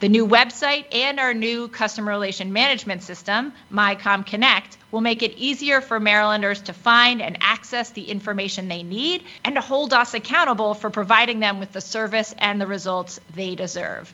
In a Tuesday press conference, Lierman said these improvements are designed to keep more money in taxpayer pockets…